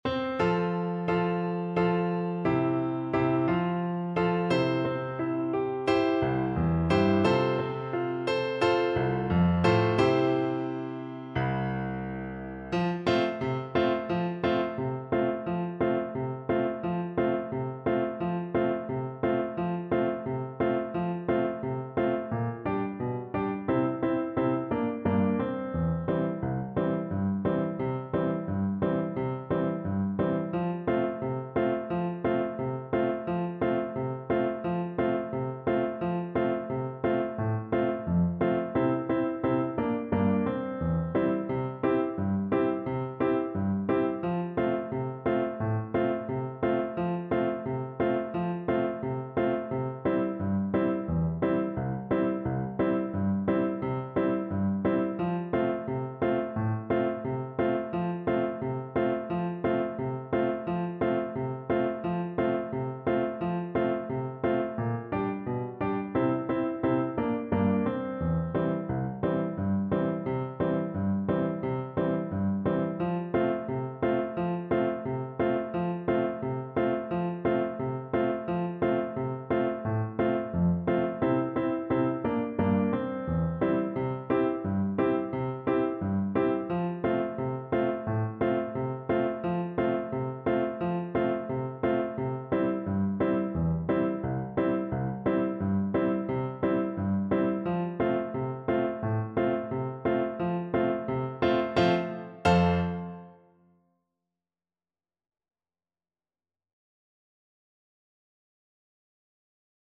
Flute
F major (Sounding Pitch) (View more F major Music for Flute )
2/4 (View more 2/4 Music)
Allegro (View more music marked Allegro)
Traditional (View more Traditional Flute Music)
Mexican